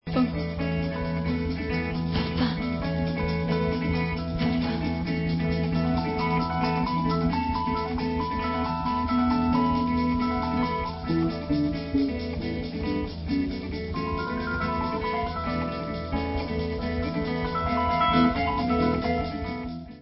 Vocal Improvisation